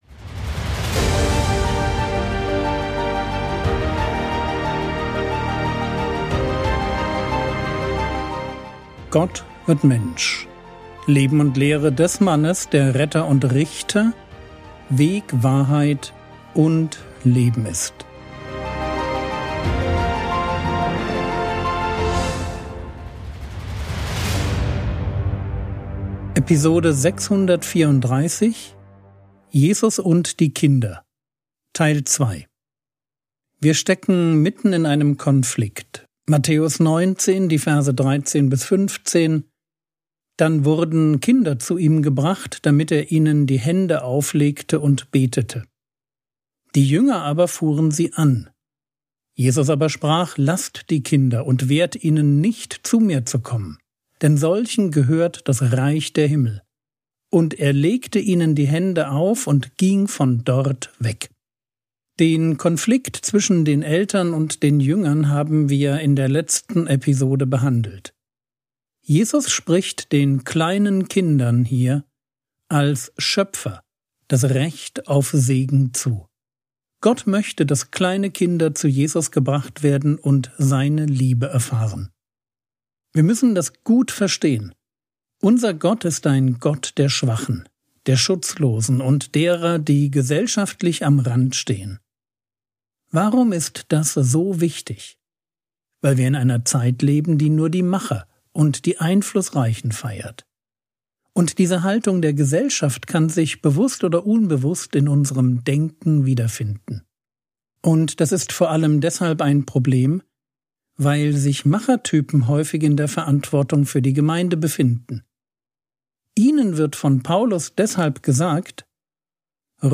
Episode 634 | Jesu Leben und Lehre ~ Frogwords Mini-Predigt Podcast